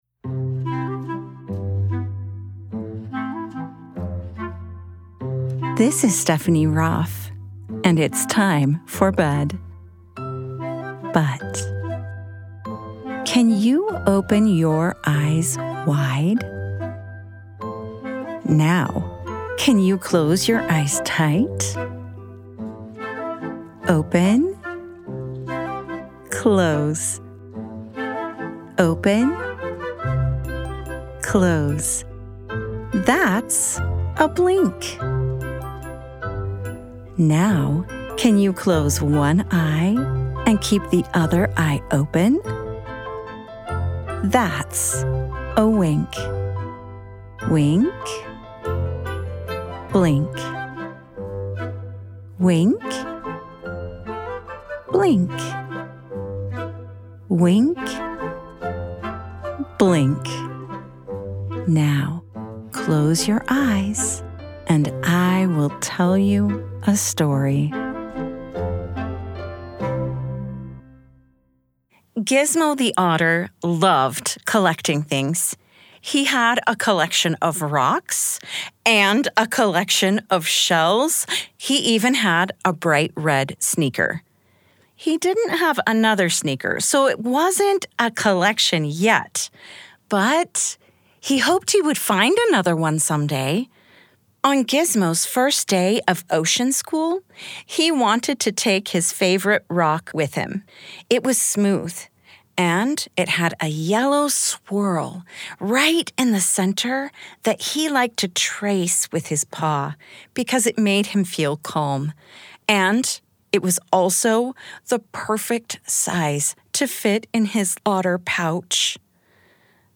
Gizmo's Magic Words: A Mindful Bedtime Story for Kids
@ wink-bedtime-stories Wink is a production of BYUradio and is always ad and interruption free.